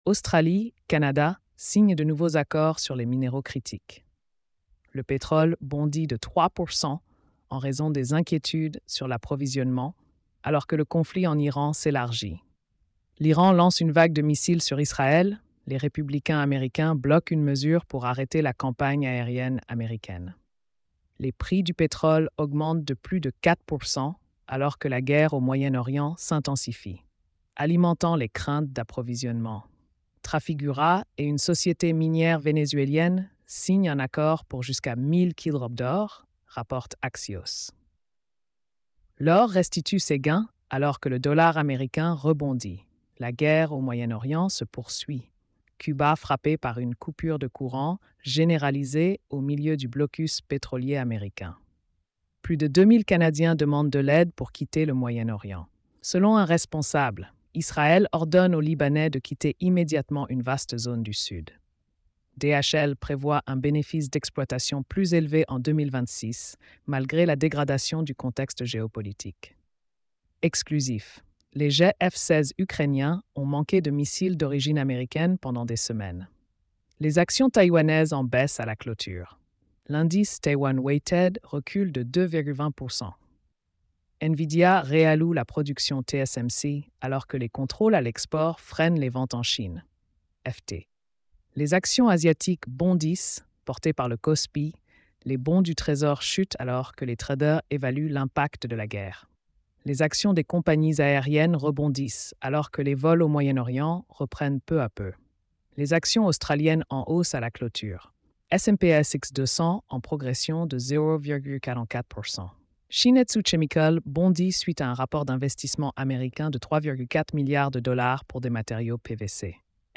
🎧 Résumé économique et financier.